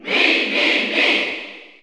Mii_Cheer_German_SSBU.ogg